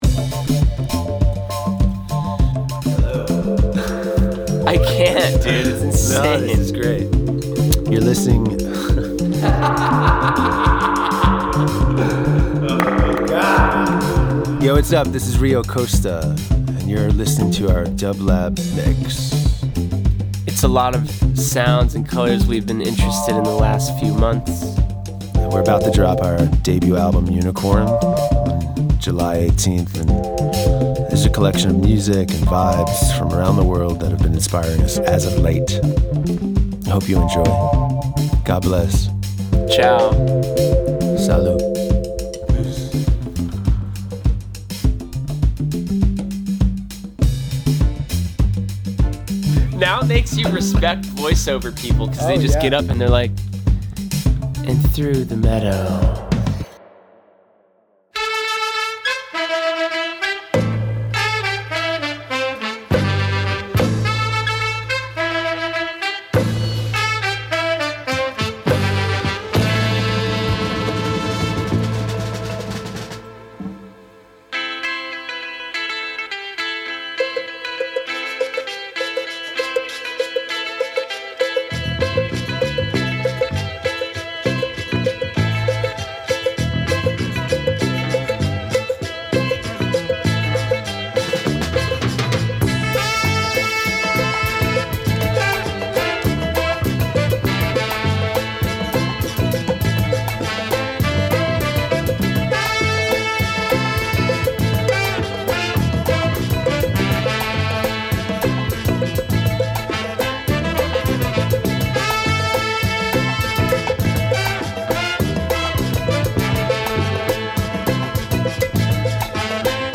Dance Jazz Latin Psych Reggaeton